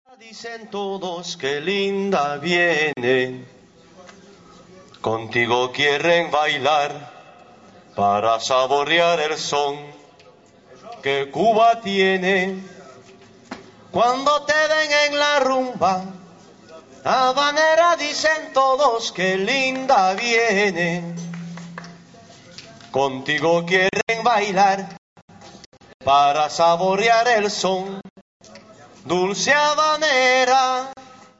FETE DE LA MUSIQUE - JUIN 2003 [retour sur l'album]